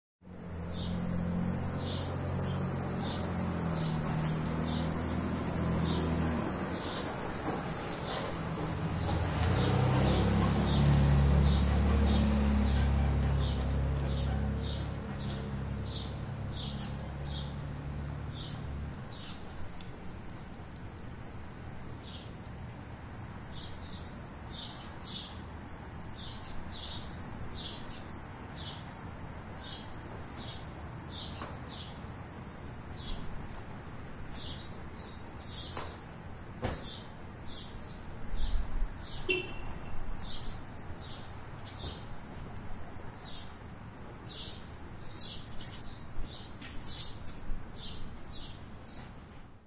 SFX " 室外环境，有鸟的停车场
描述：记录使用奥林巴斯现场记录仪制作的安静停车场。很多鸟儿和一辆车经过。